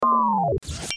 Zvukový kvíz
tyto dva relativně krátké zvuky.
Jde o zvuky, používané v sadě Microsoft Office pro oznámení prováděných akcí jako je například mazání obsahu či krok zpět.